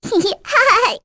Luna-Vox-Laugh2.wav